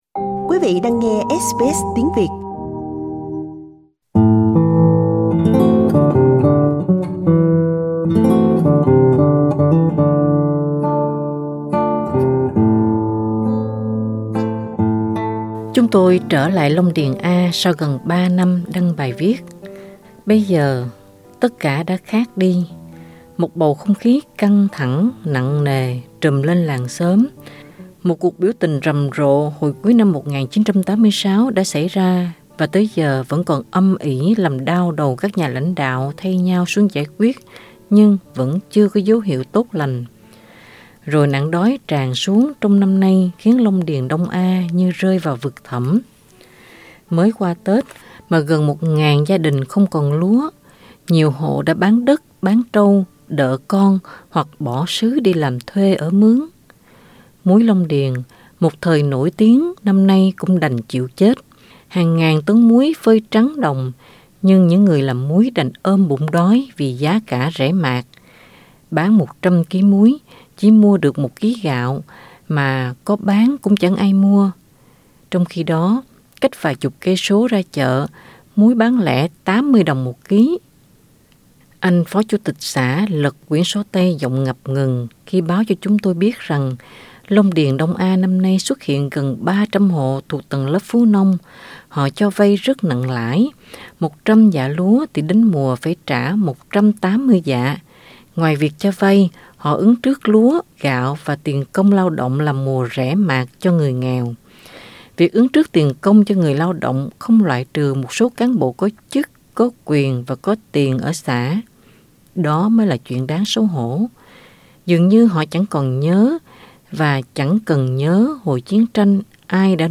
cuộc trò chuyện